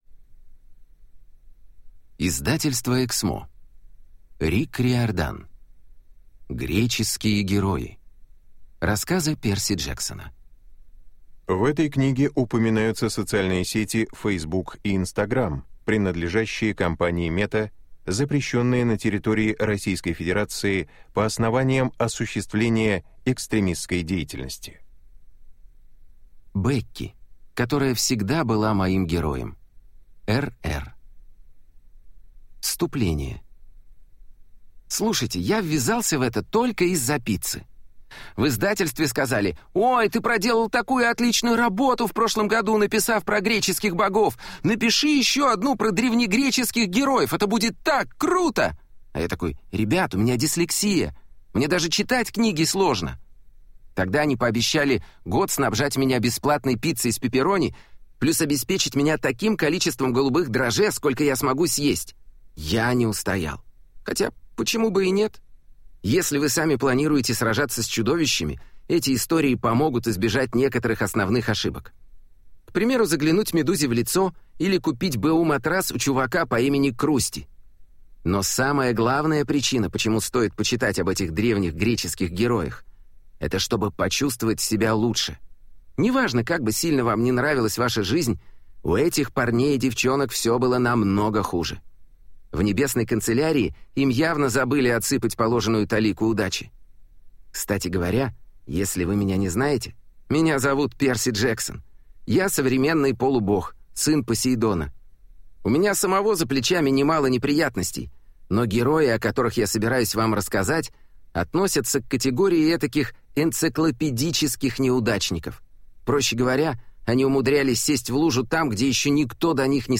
Аудиокнига Греческие герои. Рассказы Перси Джексона | Библиотека аудиокниг